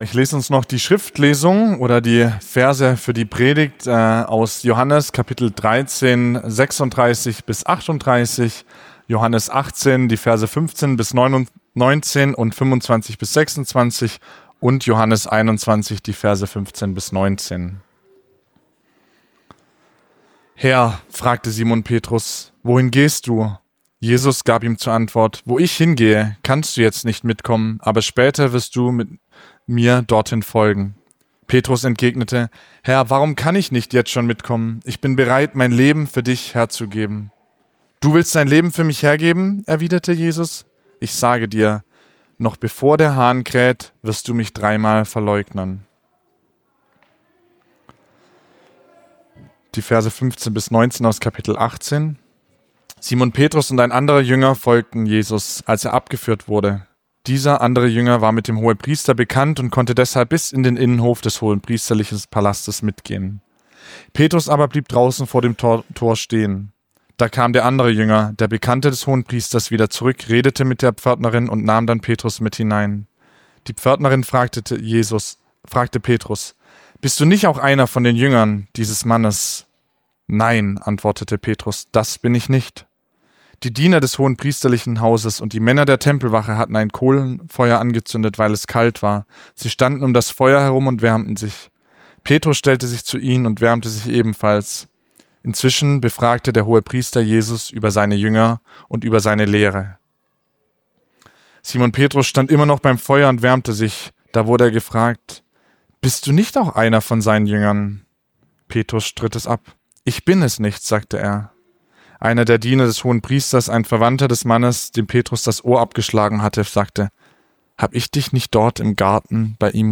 Predigt vom 10.08.2025